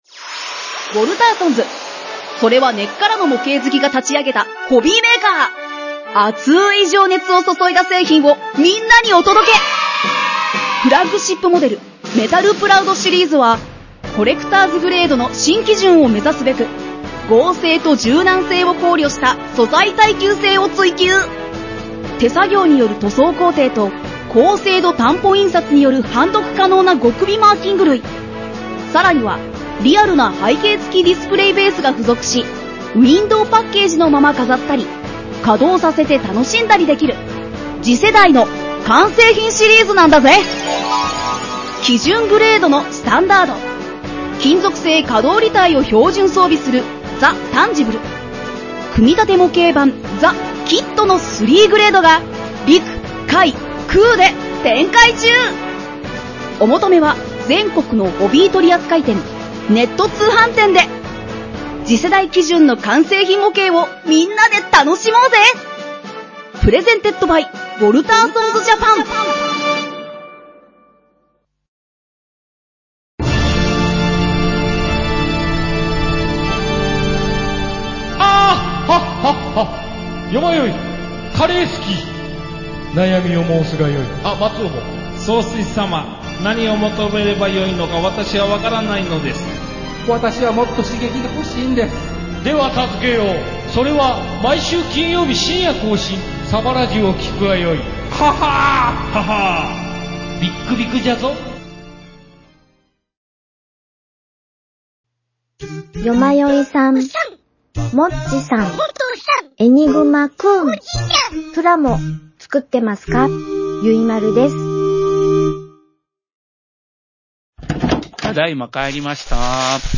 趣味が高じて模型屋「エニグマ工房」を営む事になった店主が、バイト店員や常連客たちとプラモデルの情報交換やアニメ・漫画・ミリタリーなどオタクな話題を繰り広げる…という設定のポッドキャスト番組を配信するブログです！